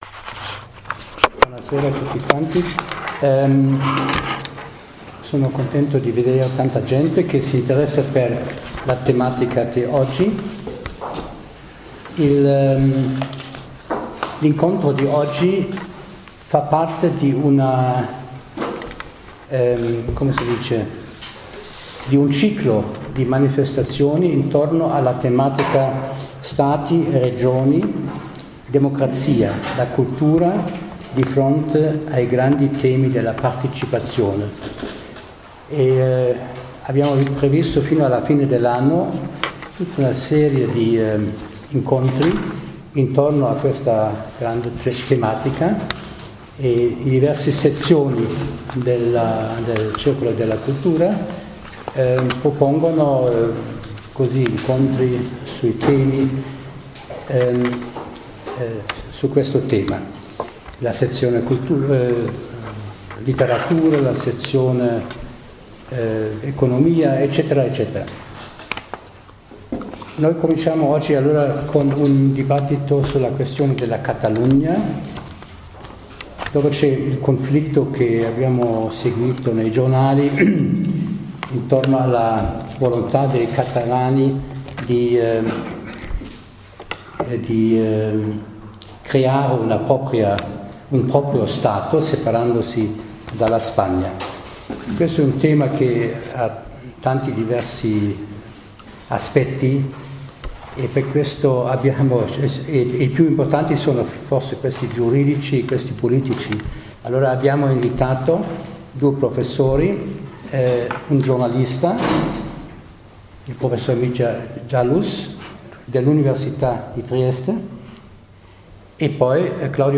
Sala conferenze della Biblioteca Statale “S. Crise”, Largo Papa Giovanni XXIII n.6